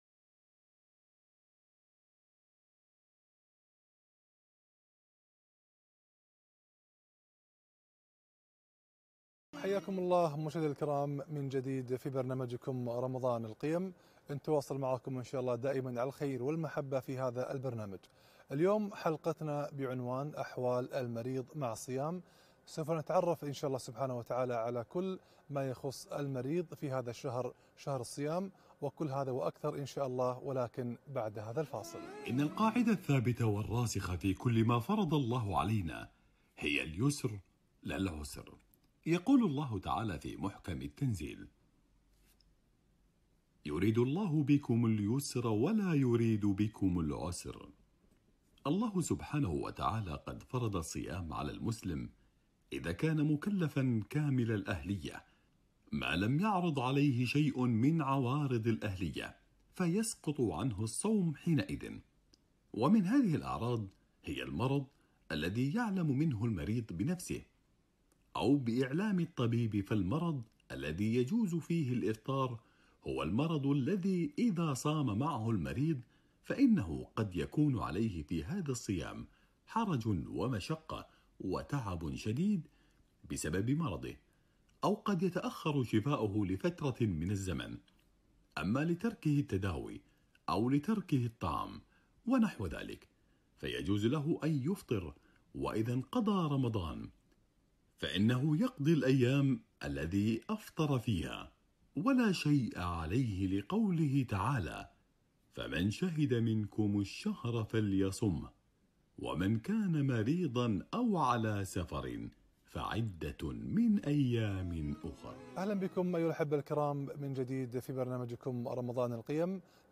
أحوال المريض مع الصيام - لقاء إذاعي